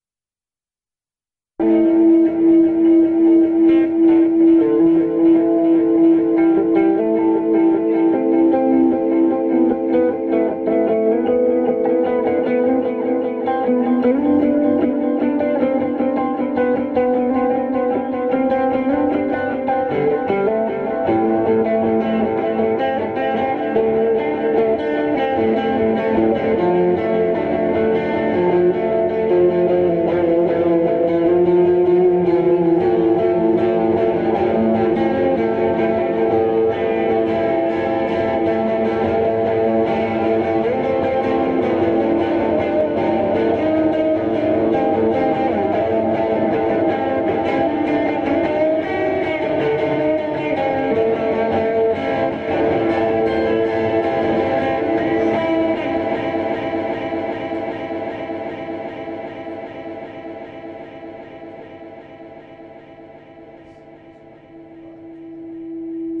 Descarga de Sonidos mp3 Gratis: ritmo 9.